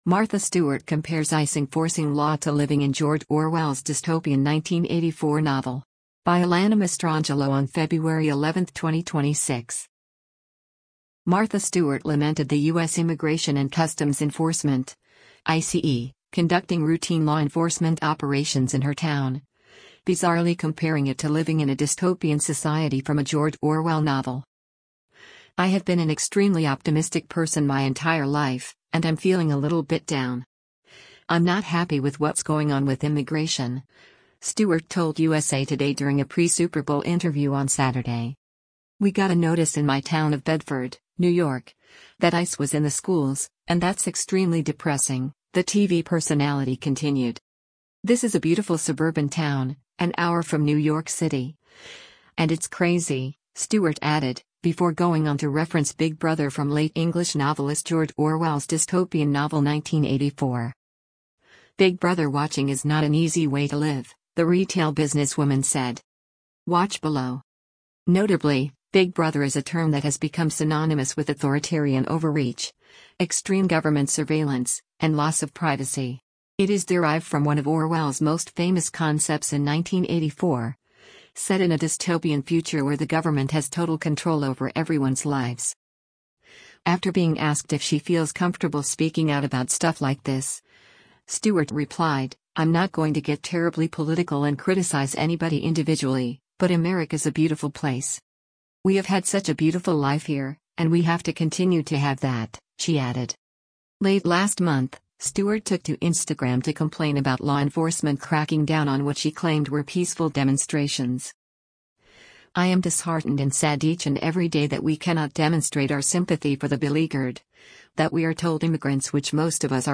“I have been an extremely optimistic person my entire life, and I’m feeling a little bit down. I’m not happy with what’s going on with immigration,” Stewart told USA Today during a pre-Super Bowl interview on Saturday.